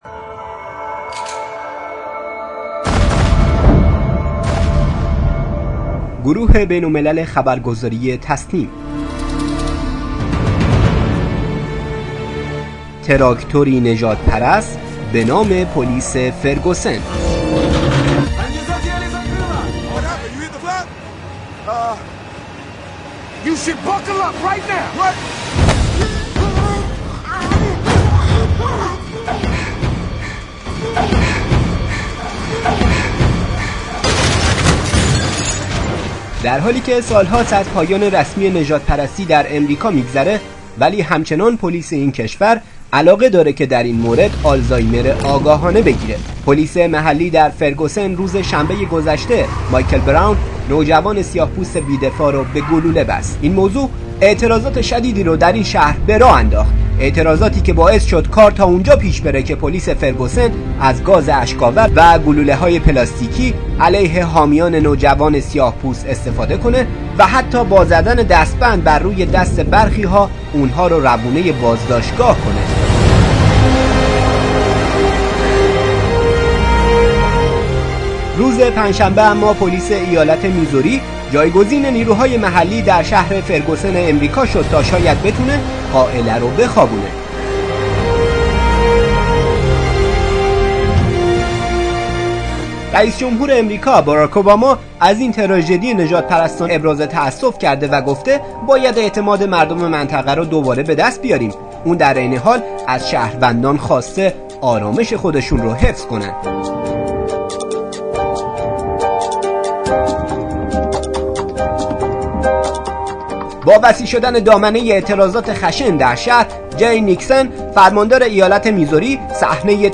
جزئیات بیشتر به اضافه صحبت های باراک اوباما را در پادکست زیر دنبال کنید.